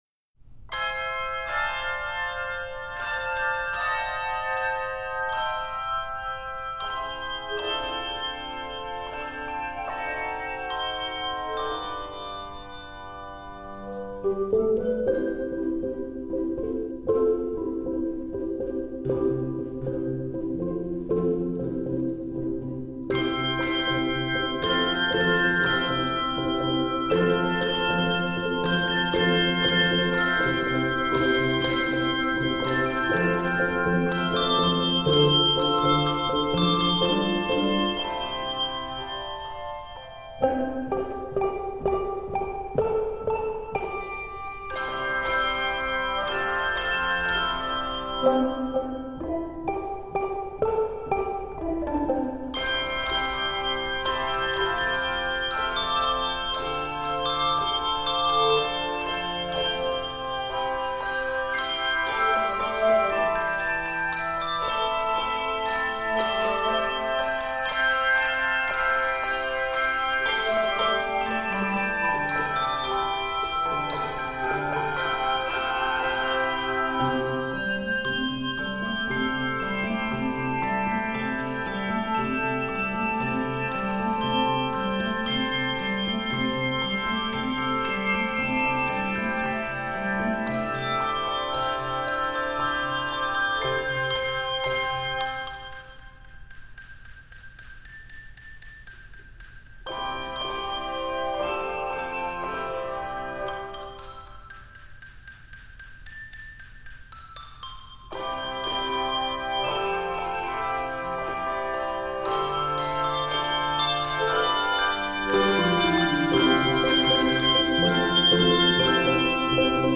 Composer: African American Spiritual
Voicing: Handbells